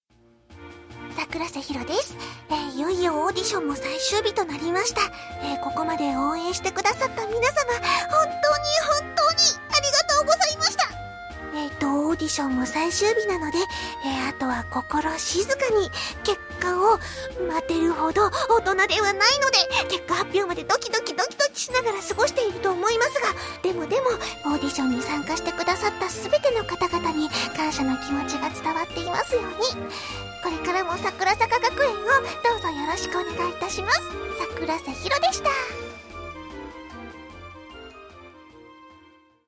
というわけで、今日はボイスメッセージです(✿╹◡╹)ﾉ